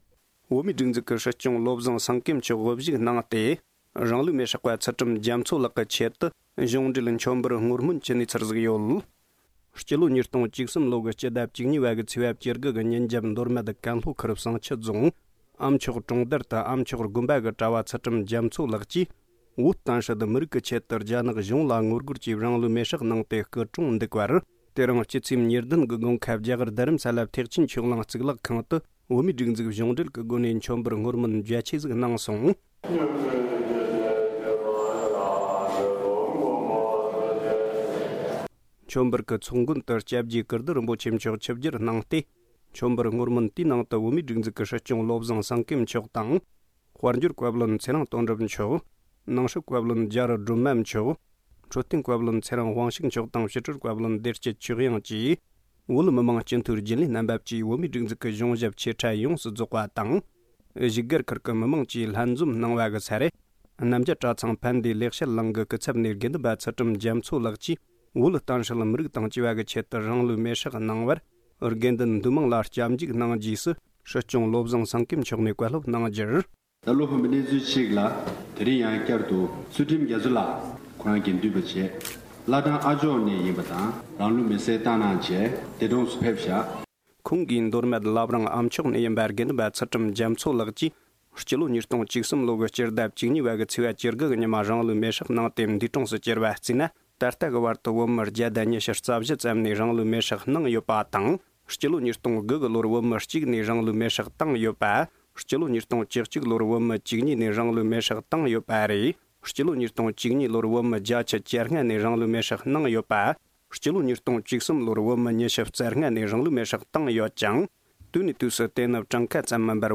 ས་གནས་ནས་བཏང་བའི་གནས་ཚུལ་ལ་གསན་རོགས༎